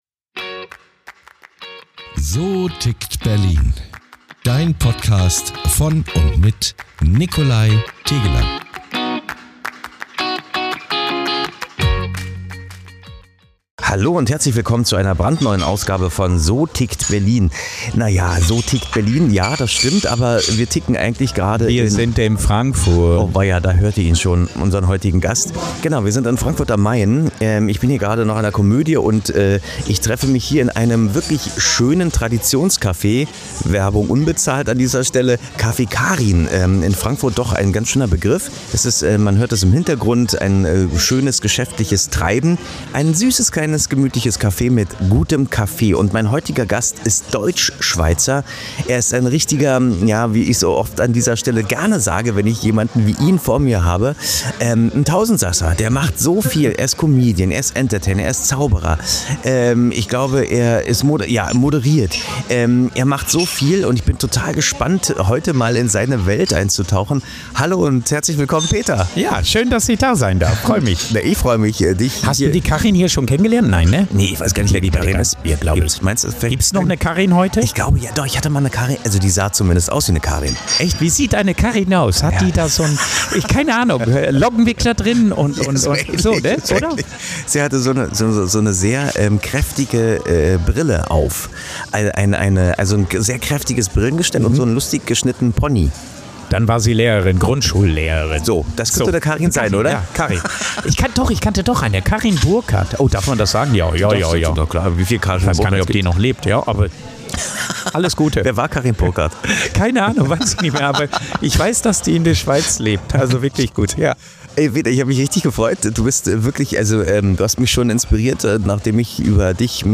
Ein warmes, ehrliches Gespräch über Humor, Menschlichkeit, Haltung – und darüber, warum man mit einem guten Witz manchmal mehr bewegen kann als mit tausend Worten.